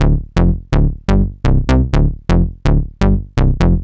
cch_bass_loop_warehouse_125_C.wav